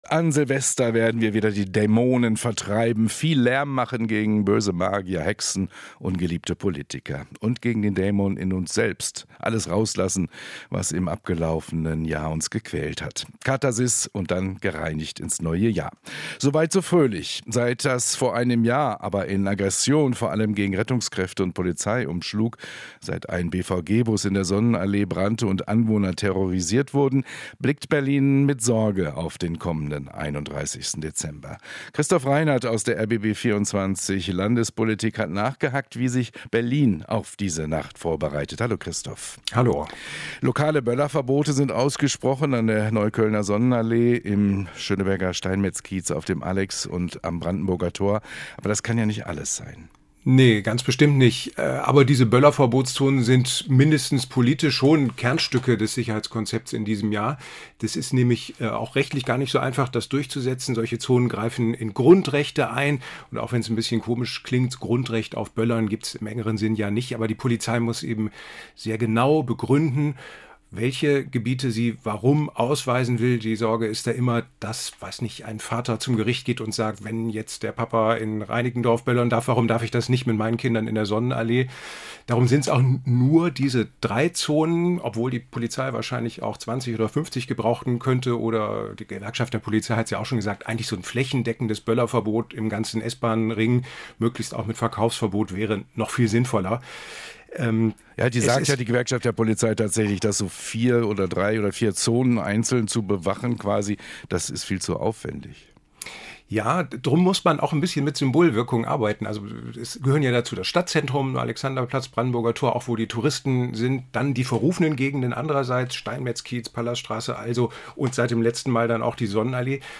Interview - Wie sich Berlin auf die Silvesternacht vorbereitet